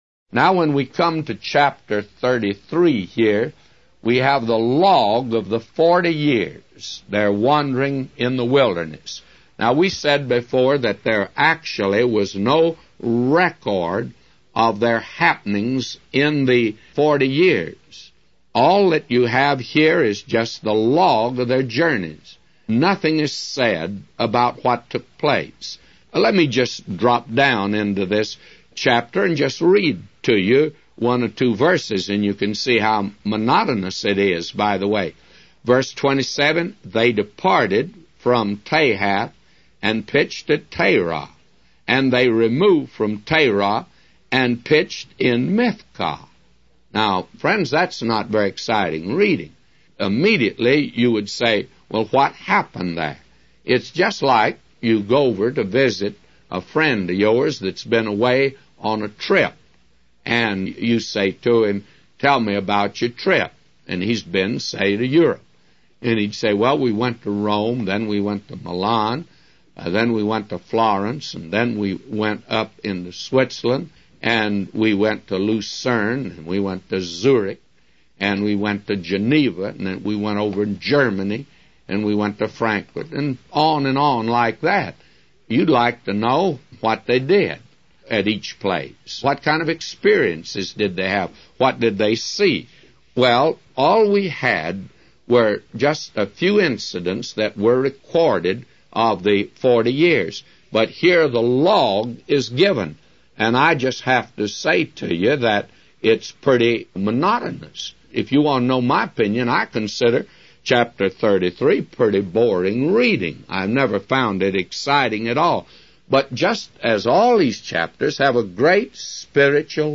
A Commentary By J Vernon MCgee For Numbers 33:1-999